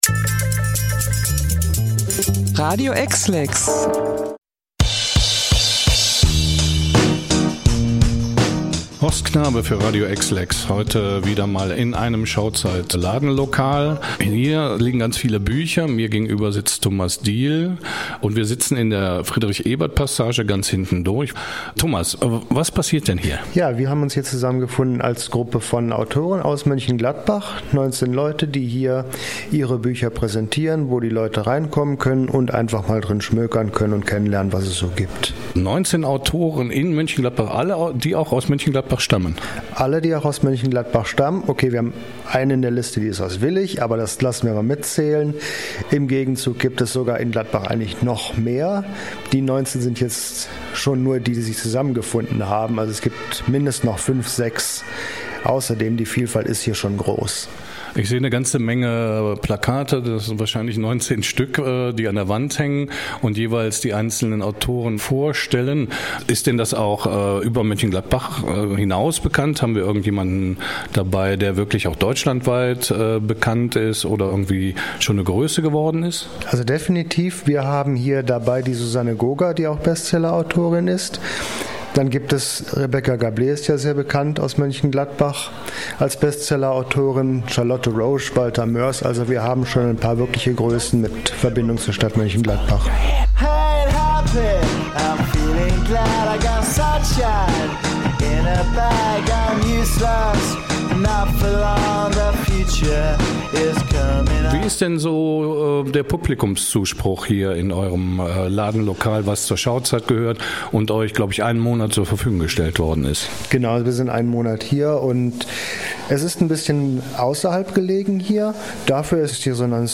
erfahrt Ihr im Interview